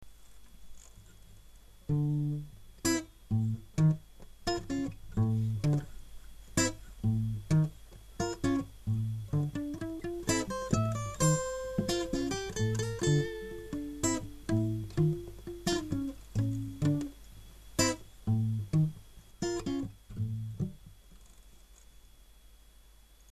Guitar arrangements